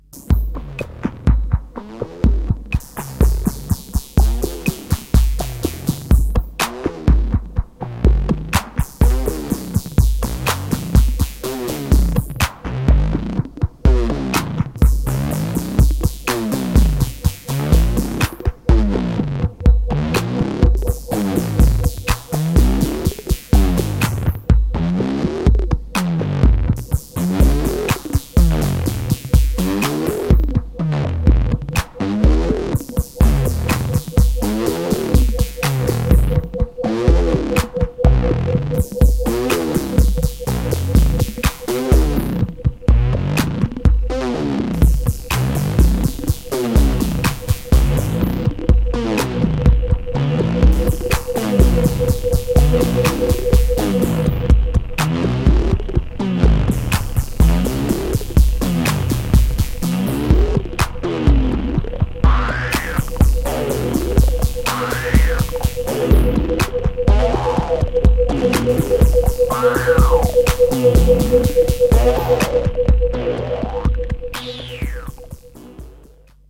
killer and highly danceable Detroit style Electronics
House Techno Detroit